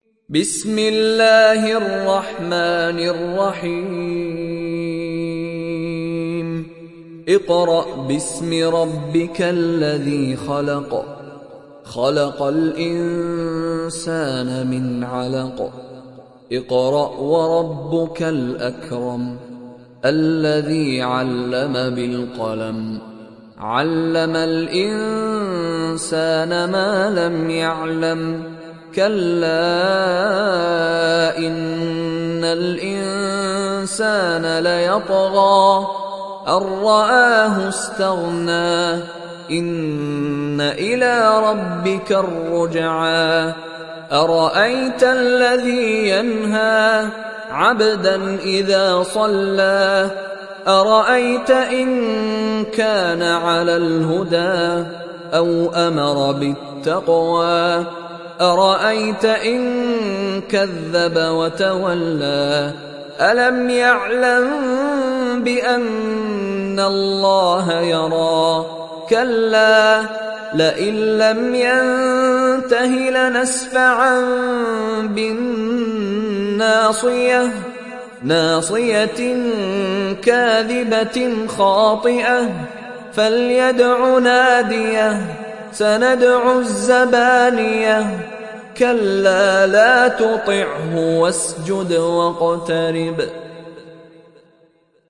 تحميل سورة العلق mp3 بصوت مشاري راشد العفاسي برواية حفص عن عاصم, تحميل استماع القرآن الكريم على الجوال mp3 كاملا بروابط مباشرة وسريعة